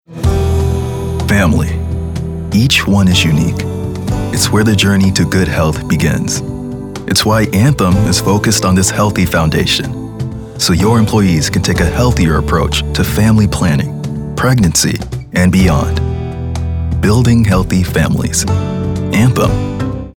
anti-announcer, caring, concerned, conversational, friendly, genuine, professional, sincere, thoughtful, warm